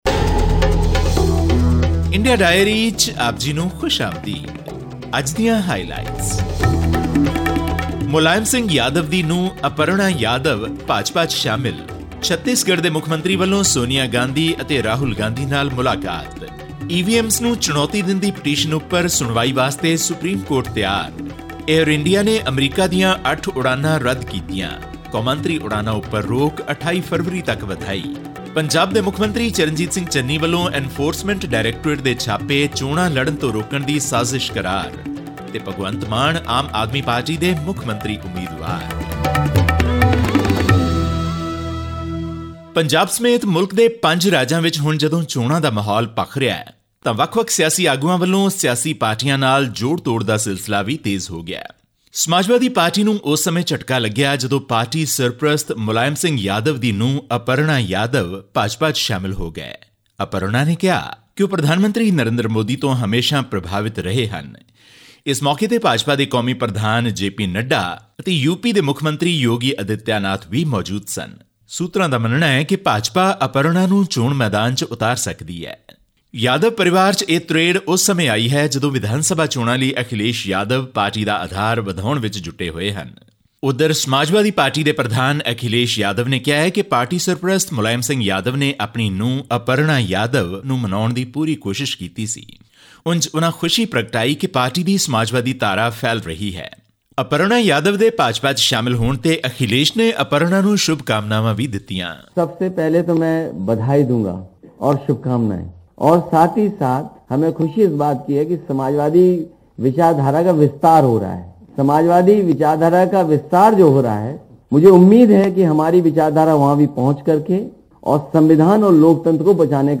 India's Director General of Civil Aviation (DGCA) has extended the current ban on international scheduled flights till February 28, 2022. The civil aviation body has also clarified that current flights under travel air bubble arrangements and Vande Bharat flights would remain unaffected from the ban. All this and more in our weekly news segment from India.